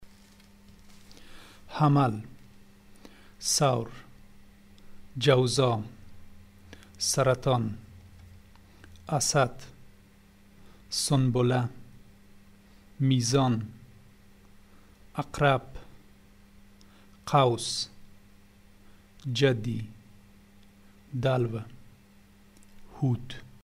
(You can listen to the month names as pronounced in two cities in Afghanistan.)
Dialect of Kabul
Dari-Kabul.mp3